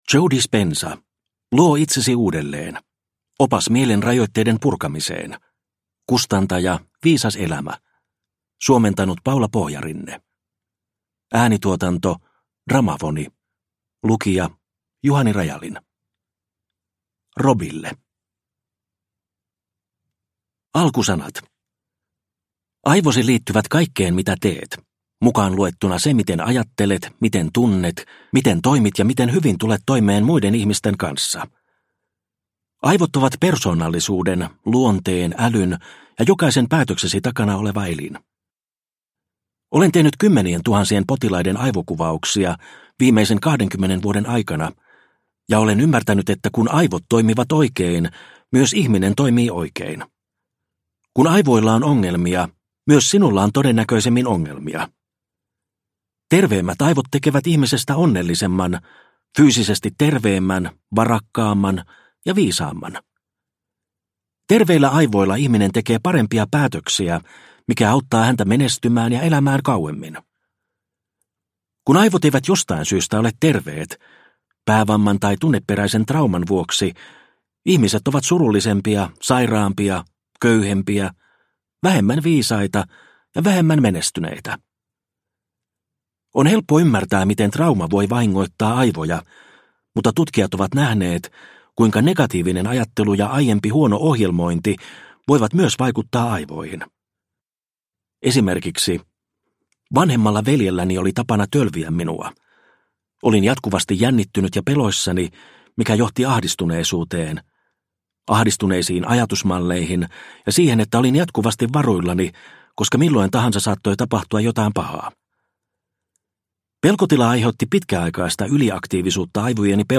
Luo itsesi uudelleen – Ljudbok – Laddas ner